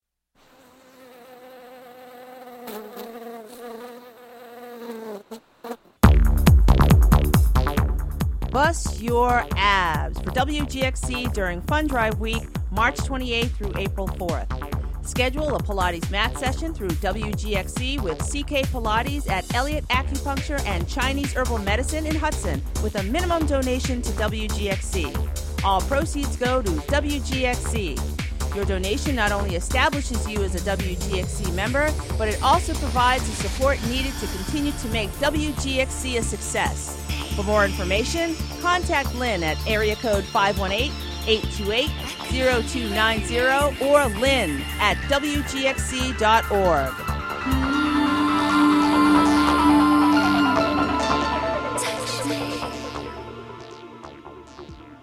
With five seconds of bees at beginning.